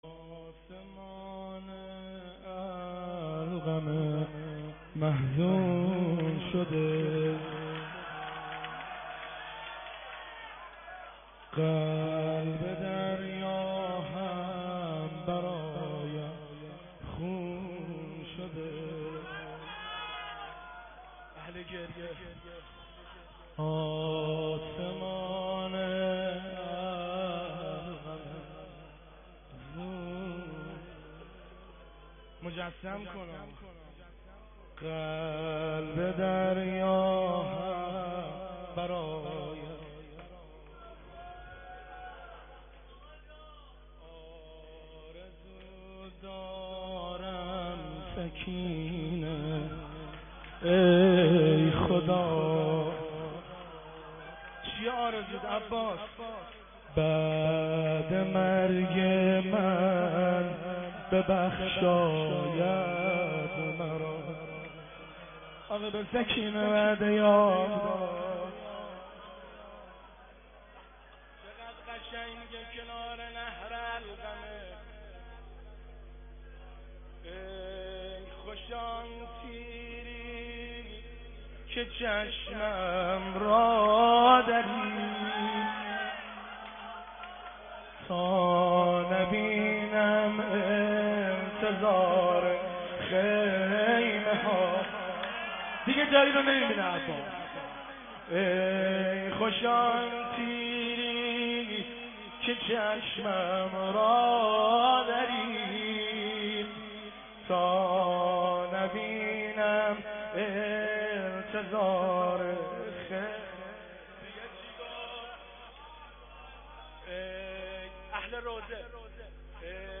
مداحی آسمان علقمه محزون شده(شعرخوانی و روضه)
شهادت امام کاظم(ع) و سومین اجتماع مدافعان حرم زینبی 1392